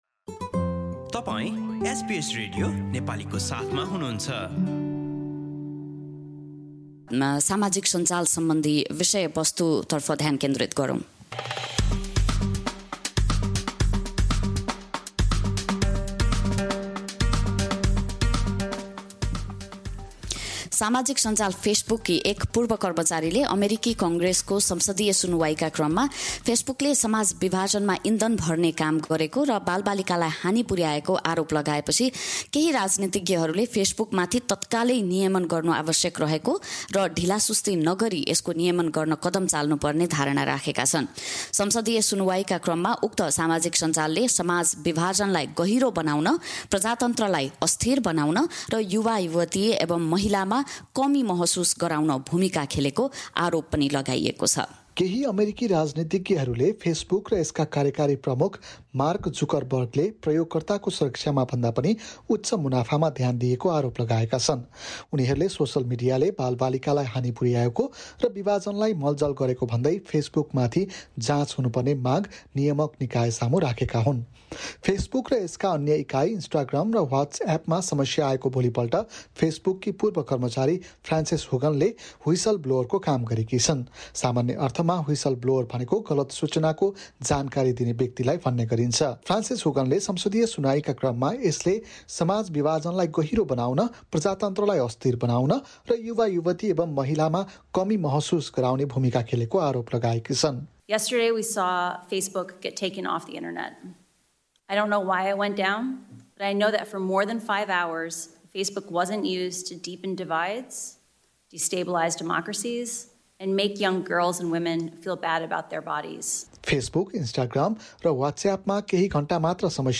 रिपोर्ट सुन्नुहोस: null हाम्रा थप अडियो प्रस्तुतिहरू पोडकास्टका रूपमा उपलब्ध छन्।